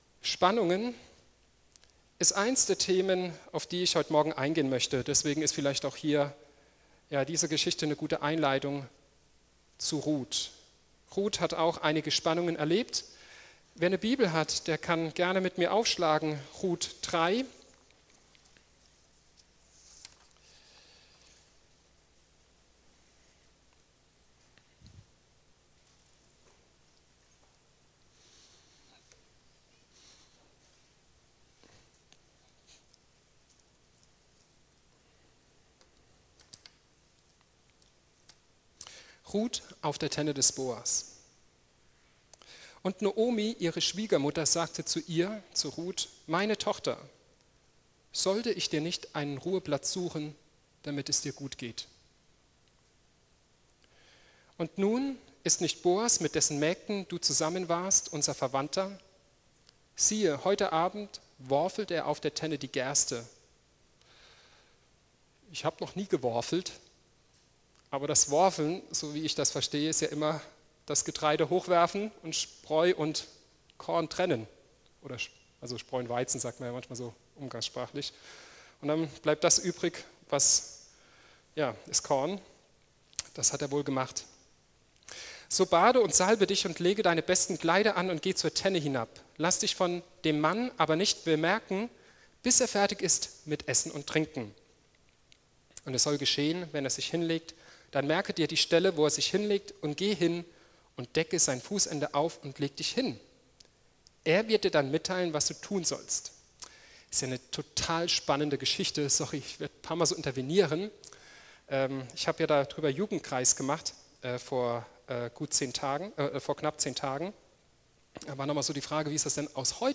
PREDIGTEN - Ev.